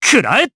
Siegfried-Vox_Attack4_jp.wav